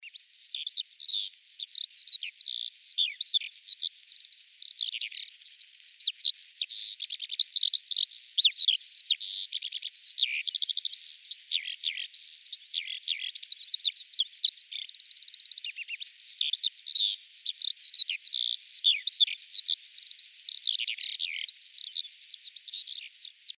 На этой странице вы найдете подборку звуков жаворонка – от звонких утренних трелей до нежных переливов.
Жаворонок весенний звук